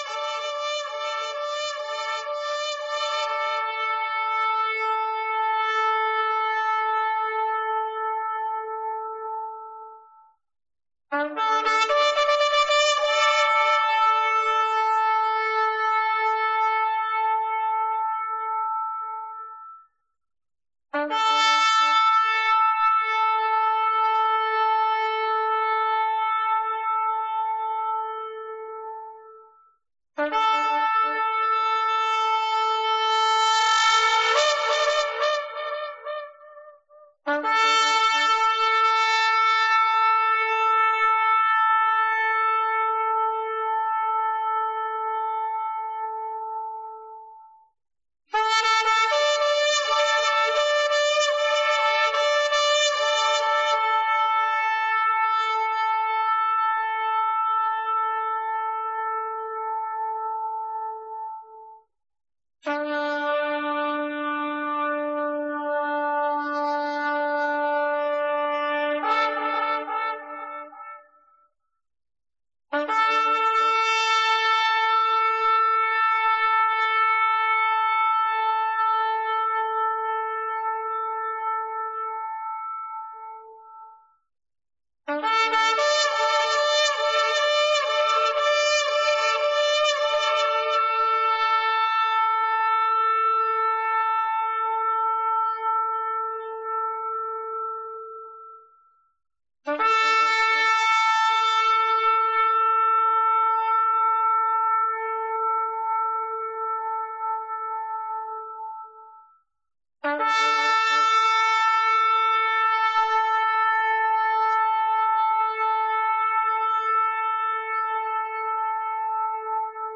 Given in Lubbock, TX
Holy Day Services Studying the bible?